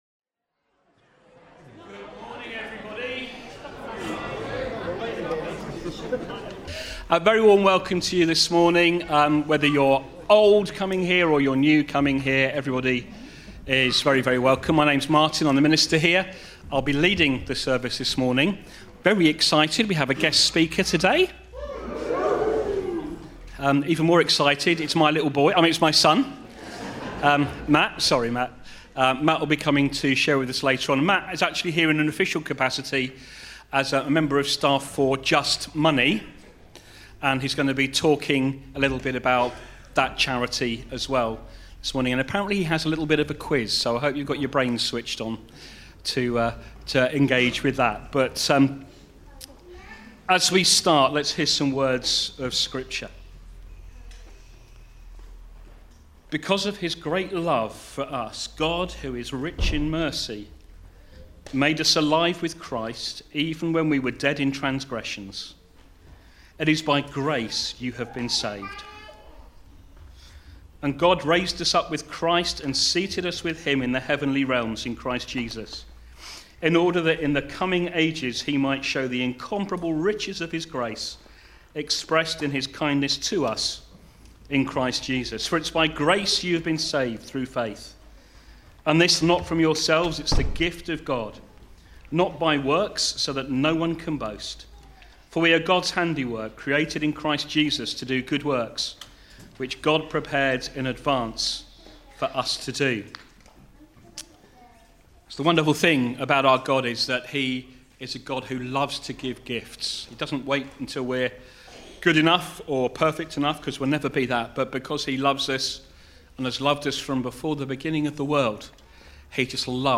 12 October 2025 – Morning Service
Service Type: Morning Service